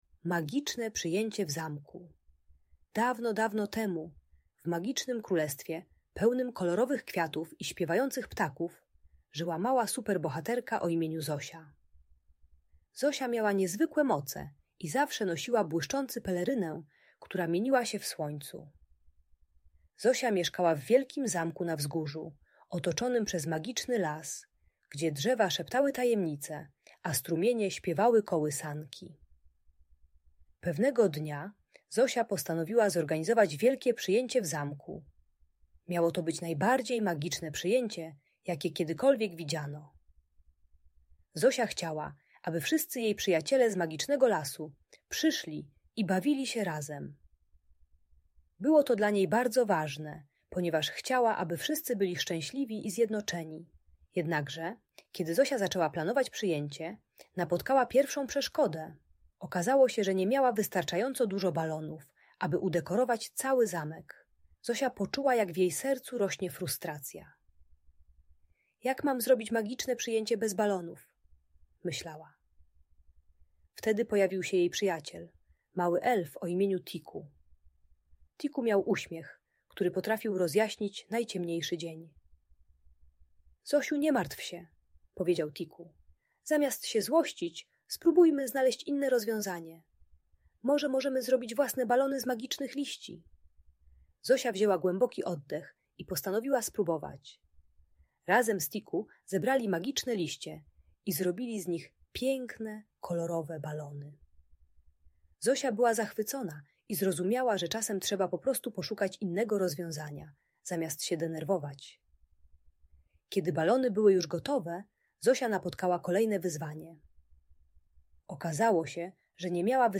Magiczne przyjęcie - Bunt i wybuchy złości | Audiobajka
Uczy techniki głębokiego oddechu oraz szukania alternatywnych rozwiązań i proszenia o pomoc zamiast wpadania w złość. Darmowa audiobajka o radzeniu sobie z frustracją.